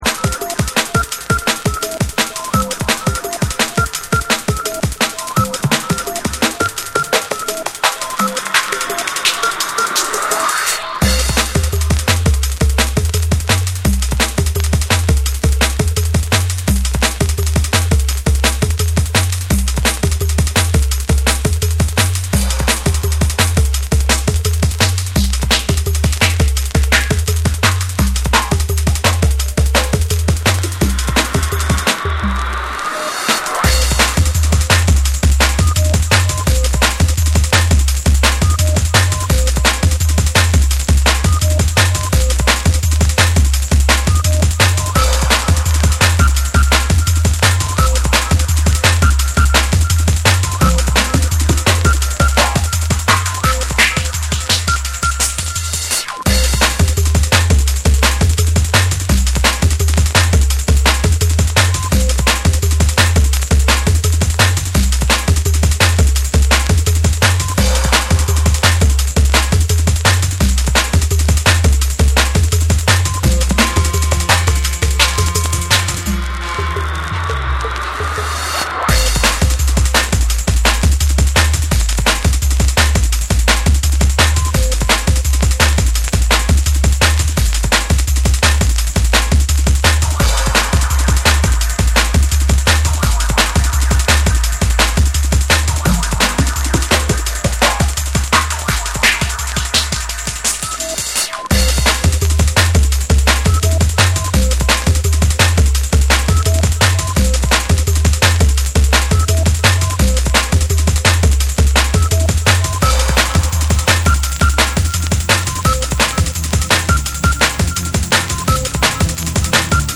攻撃的なブレイクビーツに、ハードステップなベースラインが炸裂する、フロア直撃の即戦力ドラムンベース・チューンを収録！